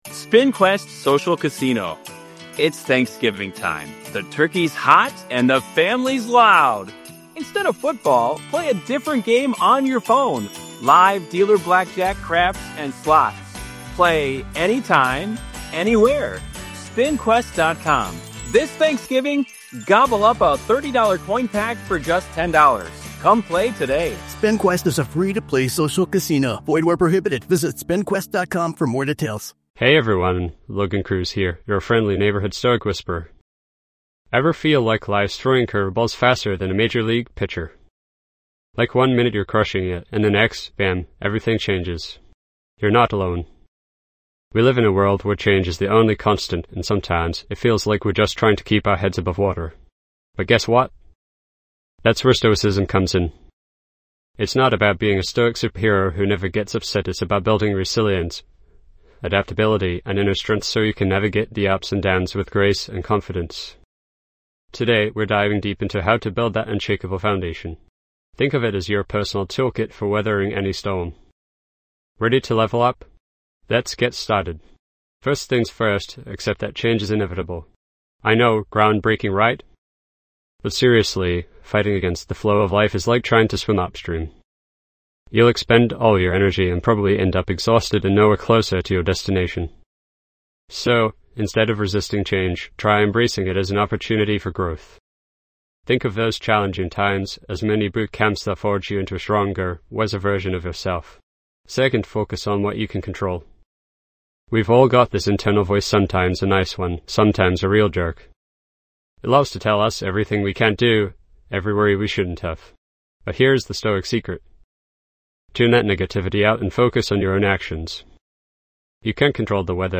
Podcast Category:. Self-Help, Personal Development, Inspirational Talks
This podcast is created with the help of advanced AI to deliver thoughtful affirmations and positive messages just for you.